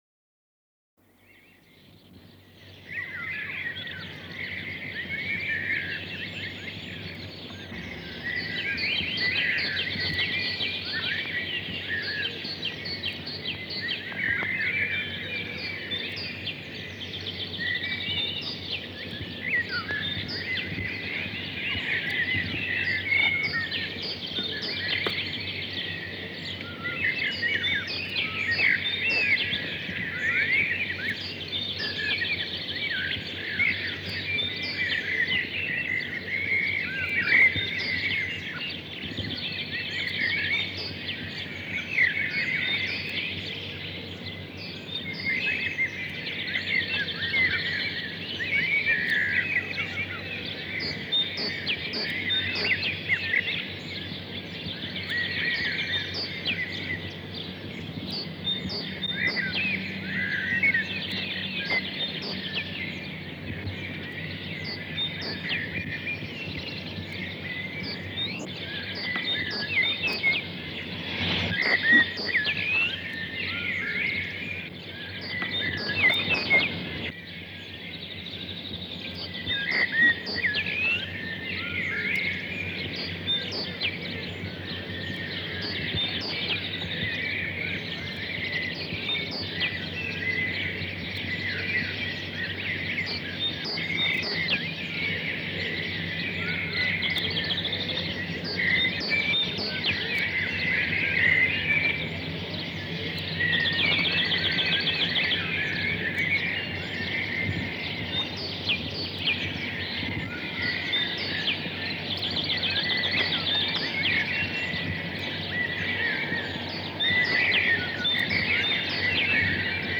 audio recording of birds with parabola receiver on the edge of the forest in Schaarsbergen in 1962 Listen to 'natura artis magistra'